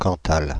Ääntäminen
Ääntäminen Paris: IPA: [kɑ̃.tal] Haettu sana löytyi näillä lähdekielillä: ranska Käännöksiä ei löytynyt valitulle kohdekielelle.